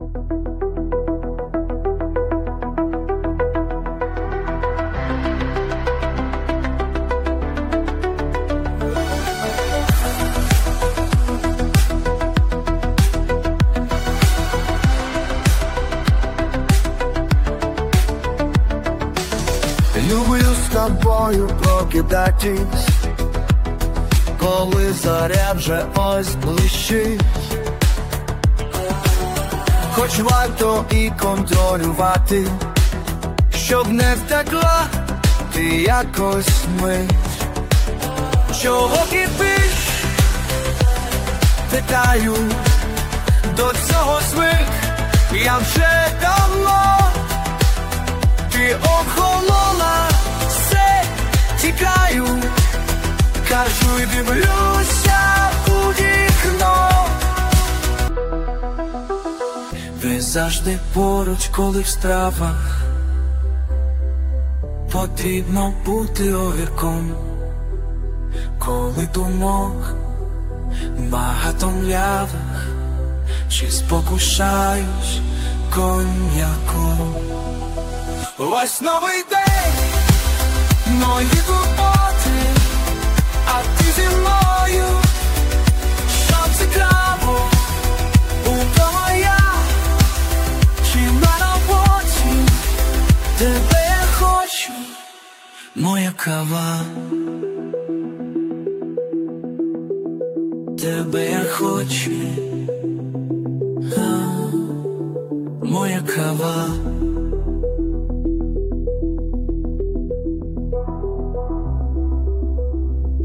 Audio_ballad.mp3
Озвучено штучним інтелектом.
ТИП: Пісня